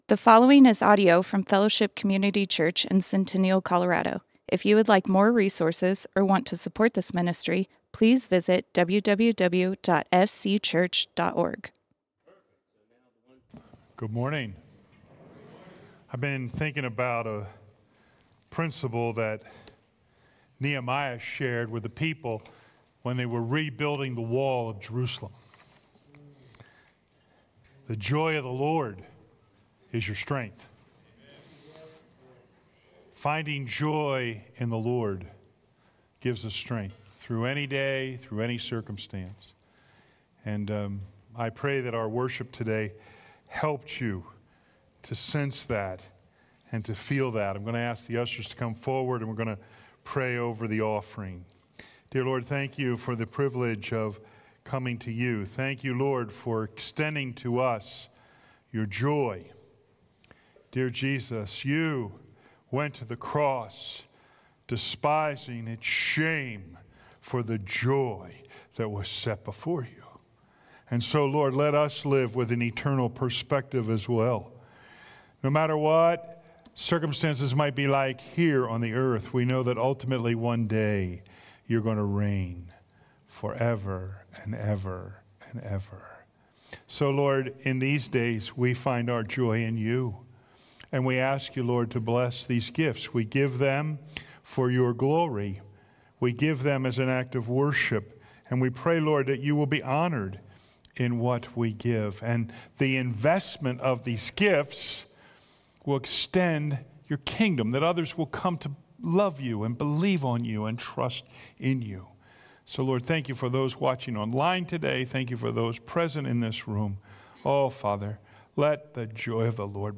Fellowship Community Church - Sermons The King is Coming Play Episode Pause Episode Mute/Unmute Episode Rewind 10 Seconds 1x Fast Forward 30 seconds 00:00 / 32:54 Subscribe Share RSS Feed Share Link Embed